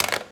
Colgar el teléfono